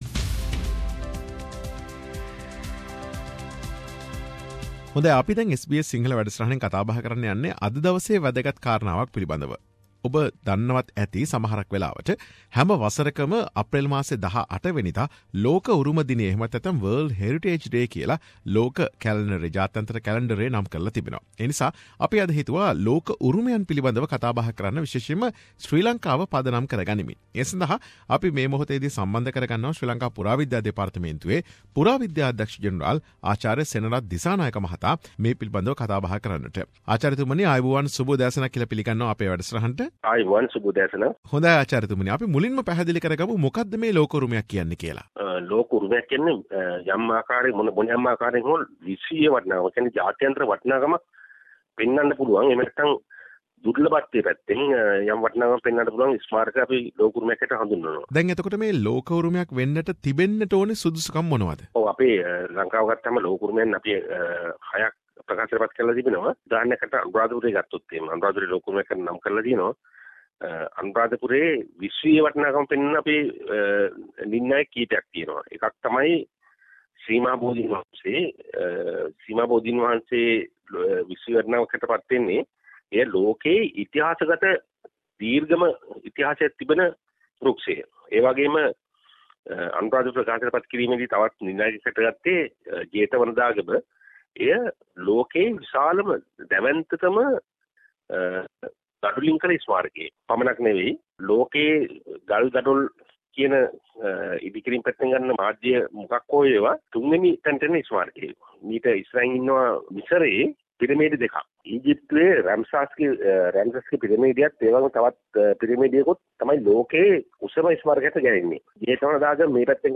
අදට යෙදී ඇති "ලෝක උරුමයන් පිළිබඳ දිනය" නිමිත්තෙන් ශ්‍රී ලංකාවේ මේ වන විට නම් කර ඇති ලෝක උරුමයන් සහ ඉදිරියේදී ලෝක උරුමයන් ලෙස නම් කිරීමට අපේක්ෂිත උරුමයන් පිළිබඳ ශ්‍රී ලංකා පුරා විද්‍යා දෙපාර්තමේන්තුවේ පුරා විද්‍යා අධ්‍යක්ෂ ජනරාල්, ආචාර්ය සෙනරත් දිසානායක මහතා සමග SBS සිංහල සිදු කළ සාකච්ඡාව.